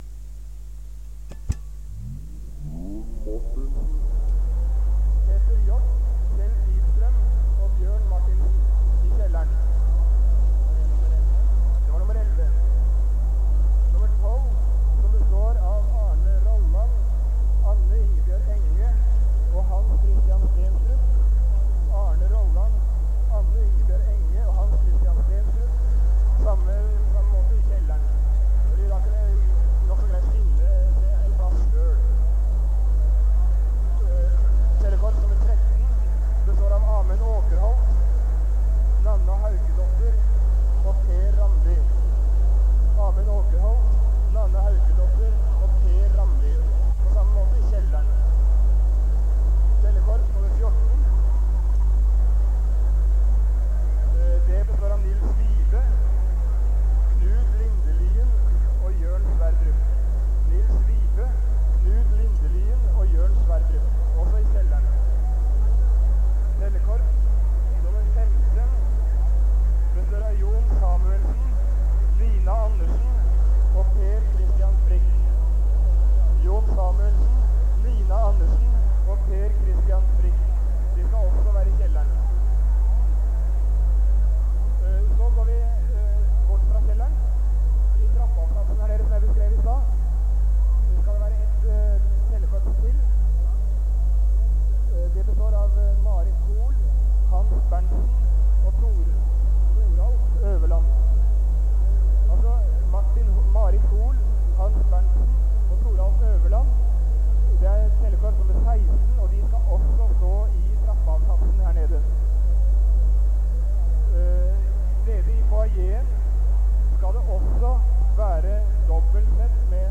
Lydopptak
Dårlig lyd